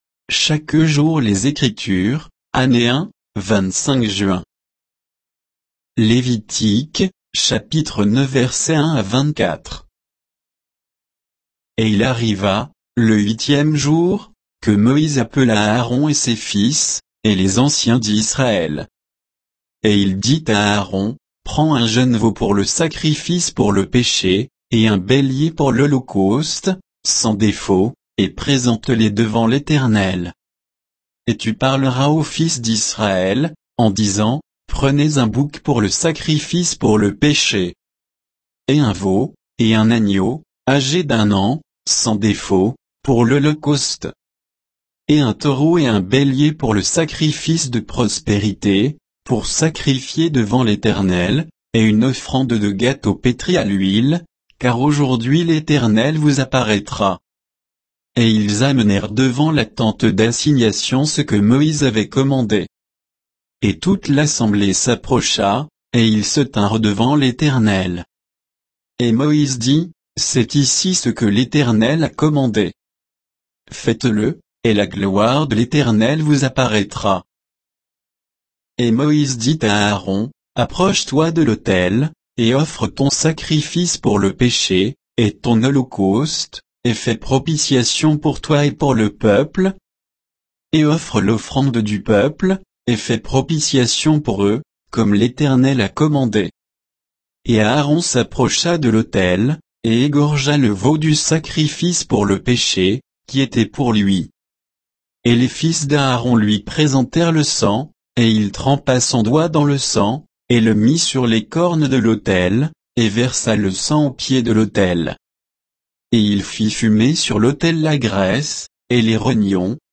Méditation quoditienne de Chaque jour les Écritures sur Lévitique 9